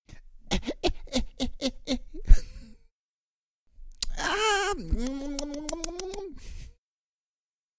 На этой странице собраны разнообразные звуки голода: от тихого урчания до громкого бурчания пустого желудка.
Реалистичные звуки пищеварительной системы, которые точно передают ощущение голода.
Звук, где мужчина дико хочет есть